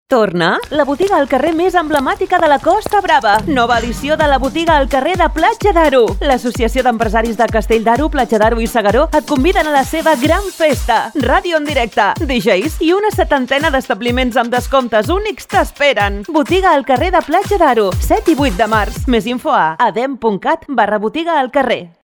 Falca 40 Principals i Capital Ràdio
Falca-Radio-Botiga-al-Carrer-Platja-d_Aro-26.mp3